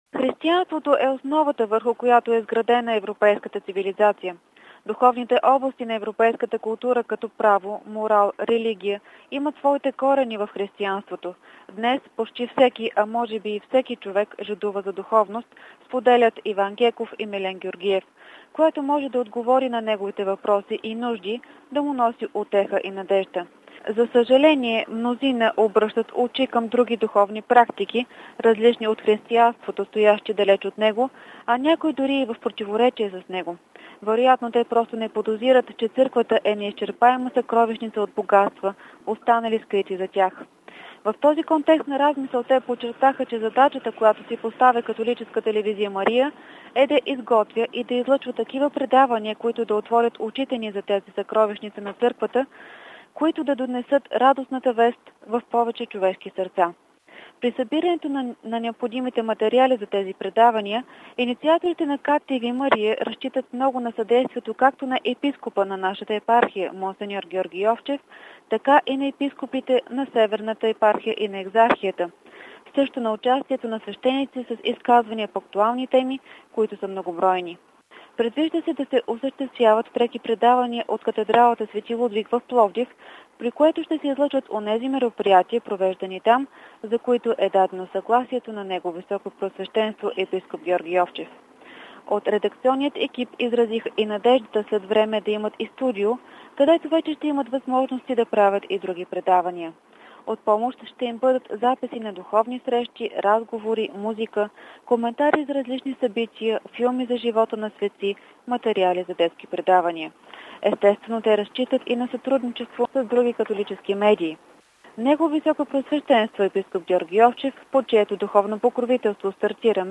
Повече за идеята, целите и програмата на новата медия научаваме от кореспонденцията